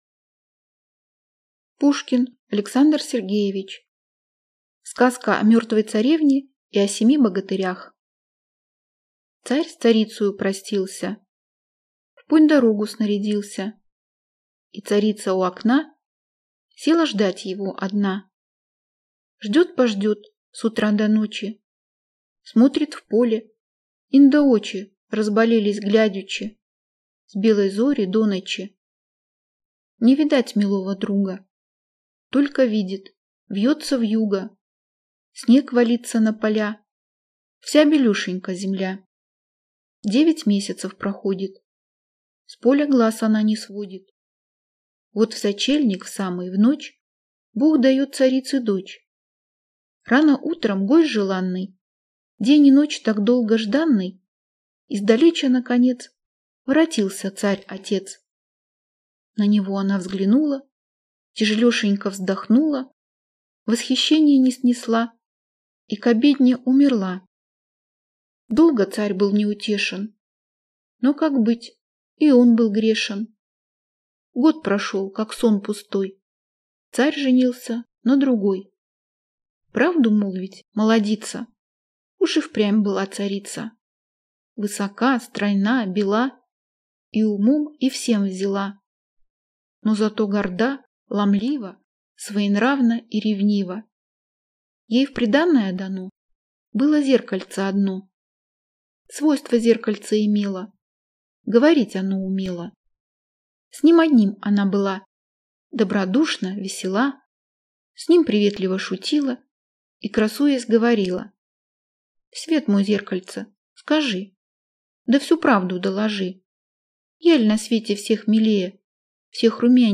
Аудиокнига Сказка о мертвой царевне и о семи богатырях | Библиотека аудиокниг